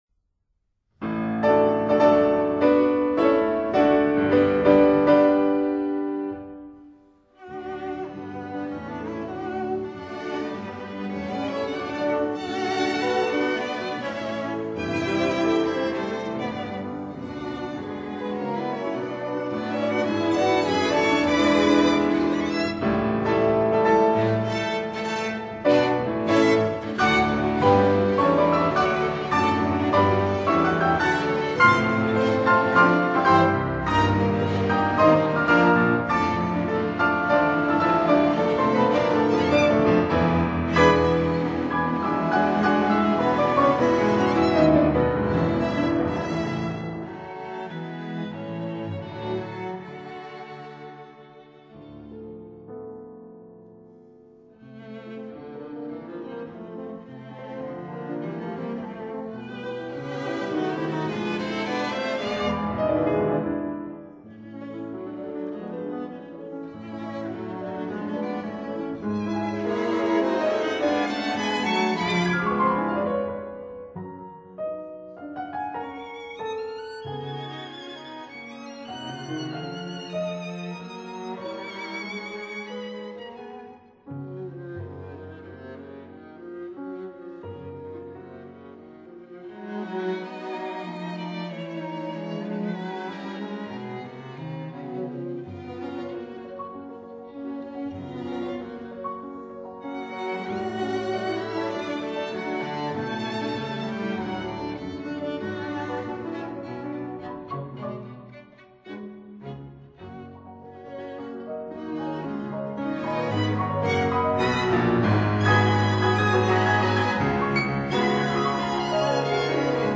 Piano Quintet in D major
Allegro moderato
piano
violin
viola
cello